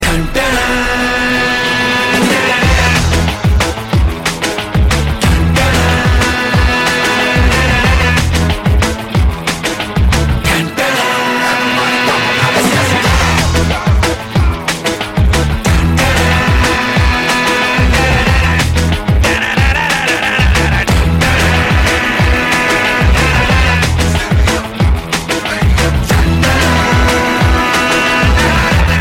new hindi song bollywood Category